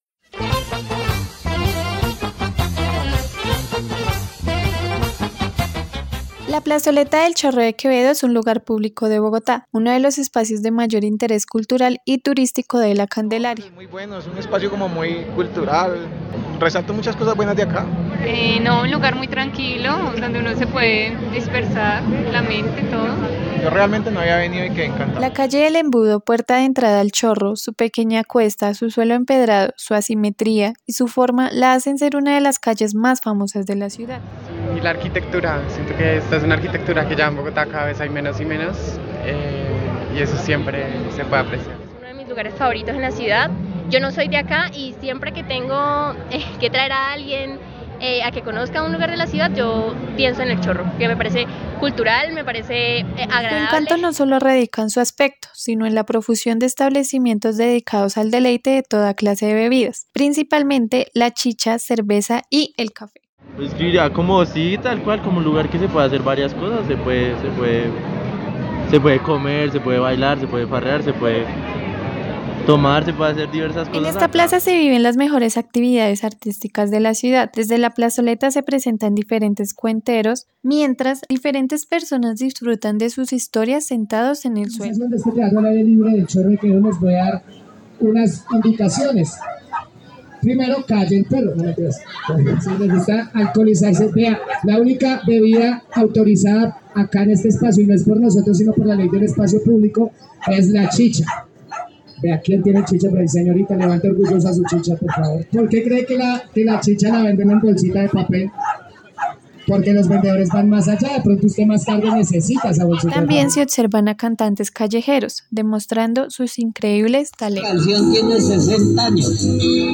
Chorro-de-Quevedo-Reportaje.mp3